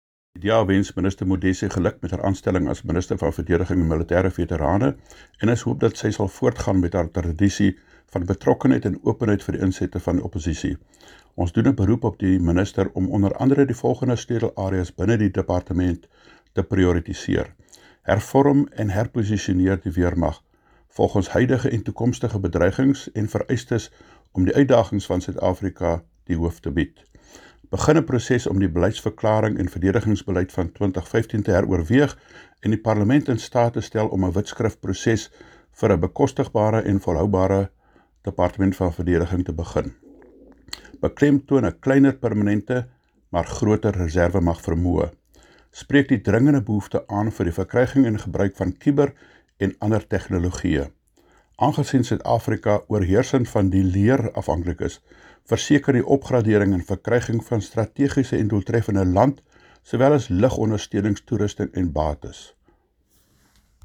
Afrikaans soundbites by Kobus Marais MP
Kobus-Afrikaans-.mp3